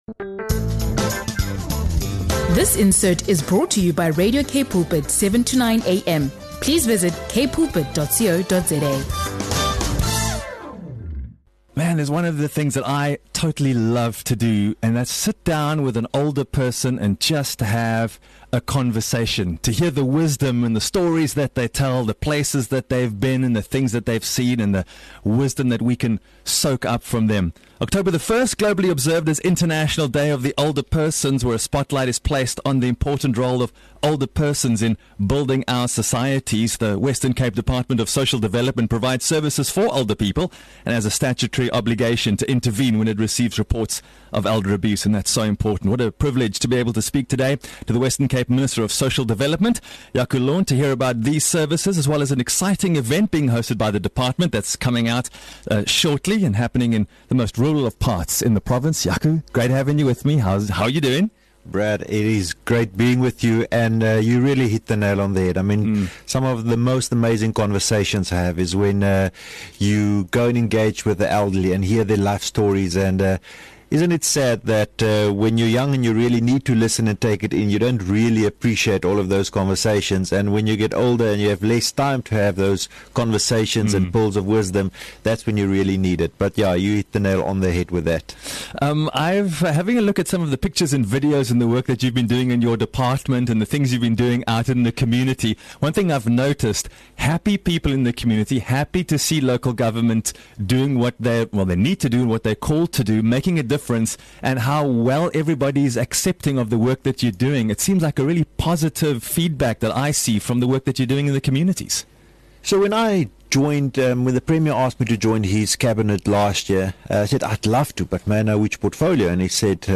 In this exclusive interview, Western Cape Minister of Social Development Jaco Londt highlights the importance of honouring older persons, tackling elder abuse, and supporting NGOs that uplift vulnerable communities. He also shares insights into the upcoming Tangkwa outreach event, where government and partners bring essential services directly to rural communities.